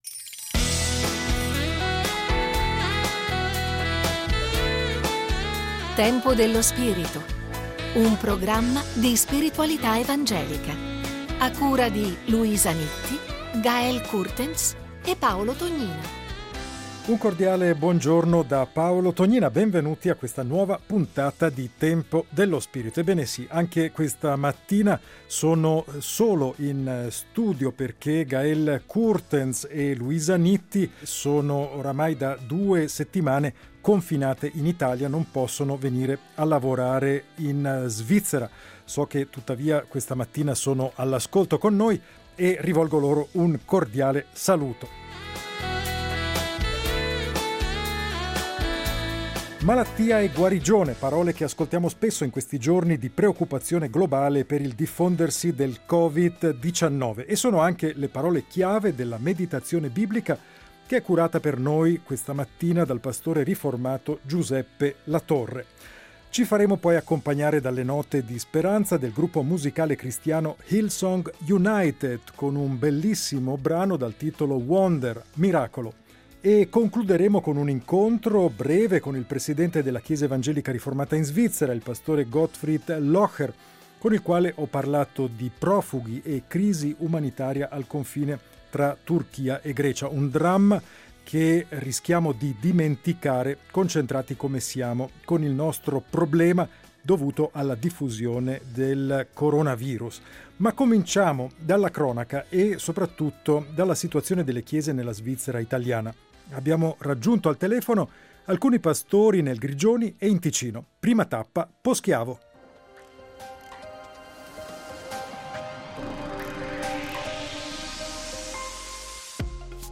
Meditazione biblica